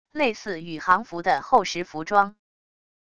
类似宇航服的厚实服装wav音频